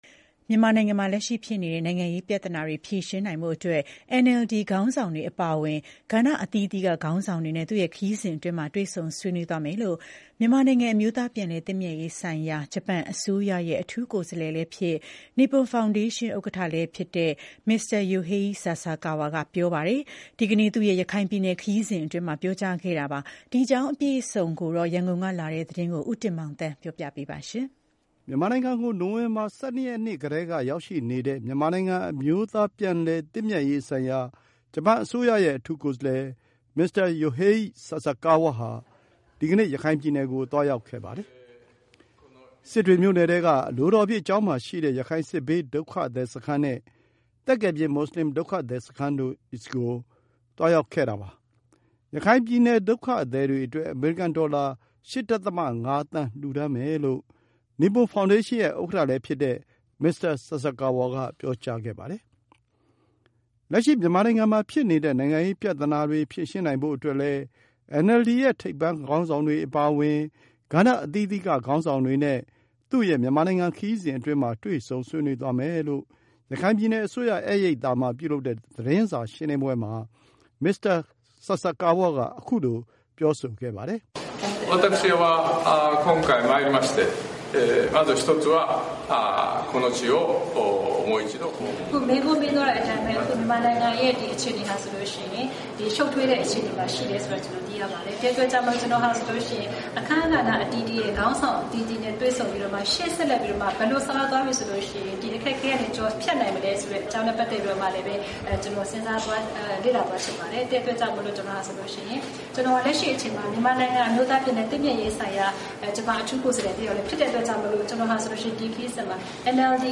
လက်ရှိမြန်မာနိုင်ငံရဲ့ နိုင်ငံရေးပြဿနာတွေ ဖြေရှင်းနိုင်ဖို့အတွက်လည်း NLD ရဲ့ ထိပ်ပိုင်းခေါင်း ဆောင်တွေအပါအဝင် ကဏ္ဍအသီးသီးက ခေါင်းဆောင်တွေနဲ့ သူ့မြန်မာနိုင်ငံခရီးစဉ်အတွင်းမှာ တွေ့ဆုံ ဆွေးနွေးသွားမယ်လို့ ရခိုင်ပြည်နယ် အစိုးရဧည့်ရိပ်သားမှာ ပြုလုပ်တဲ့ သတင်းစာရှင်းလင်းပွဲမှာ Mr. Sasakawa က ခုလို ပြောဆိုခဲ့ပါတယ်။